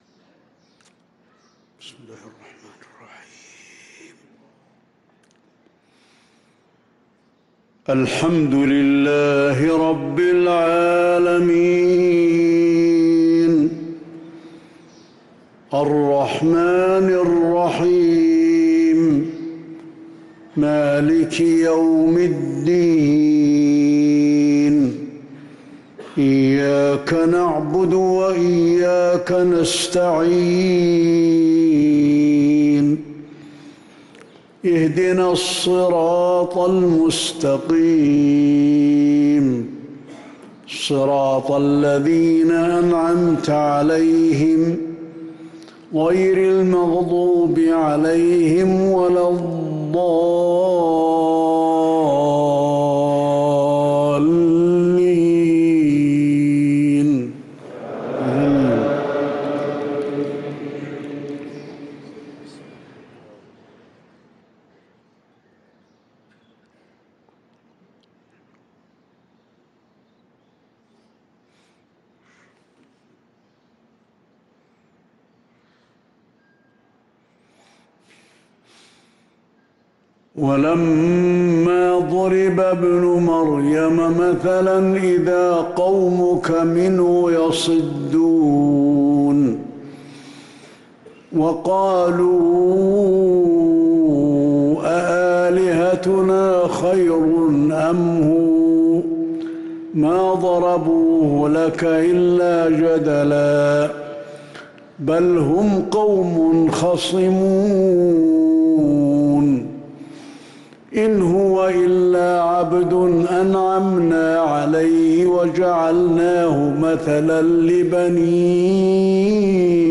صلاة الفجر للقارئ علي الحذيفي 15 ربيع الأول 1444 هـ
تِلَاوَات الْحَرَمَيْن .